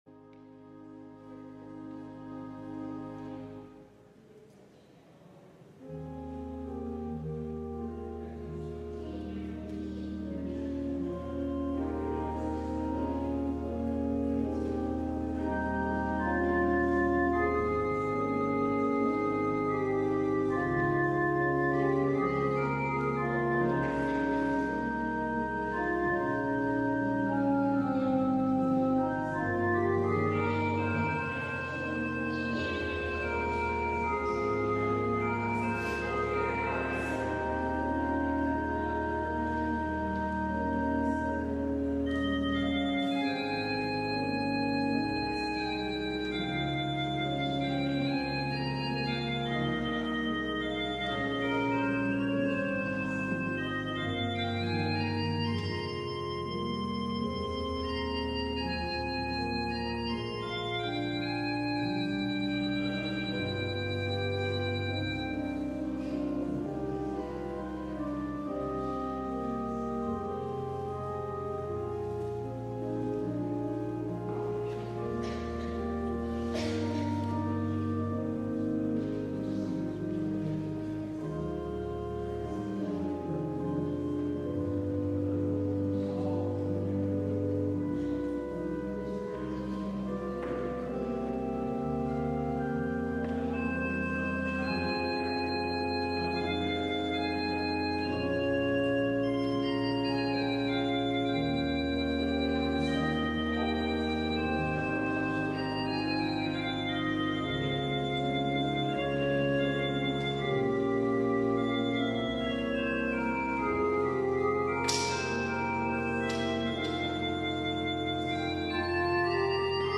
LIVE Evening Worship Service - I Will Be With You